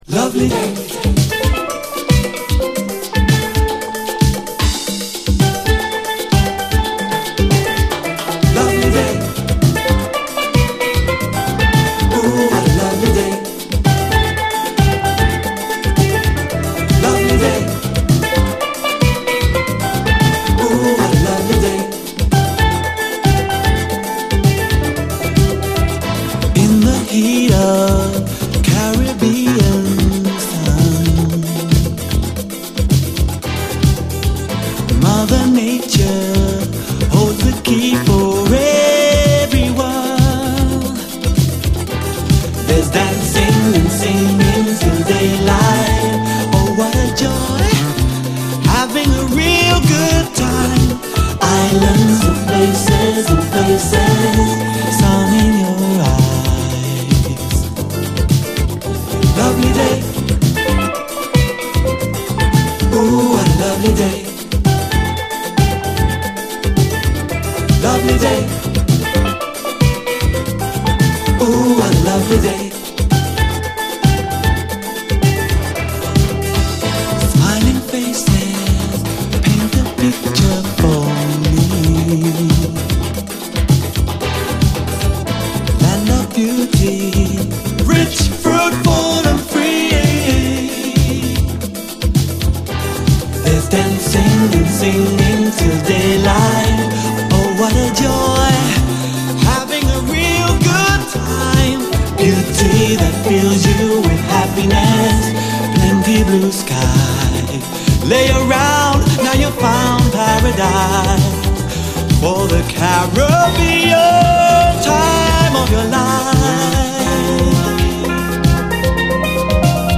レア・ガーリー・ノーザン〜ガール・ポップ45！